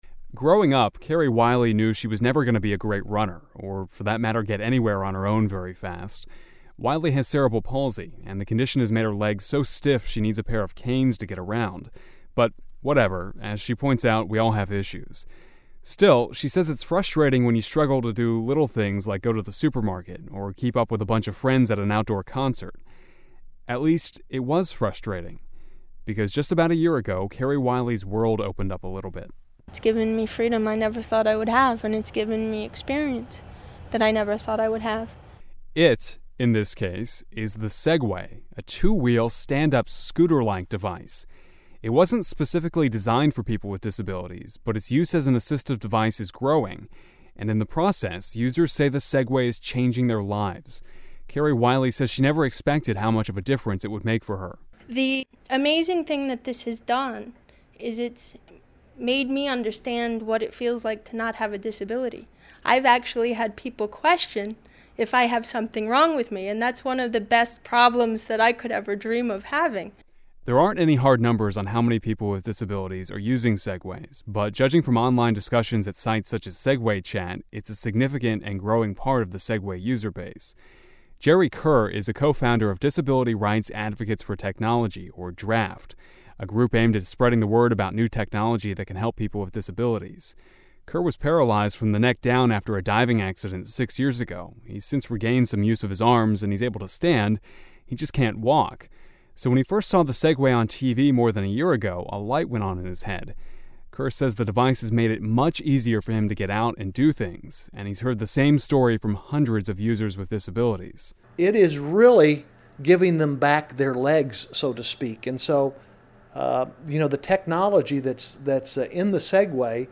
(originally aired November 11, 2004)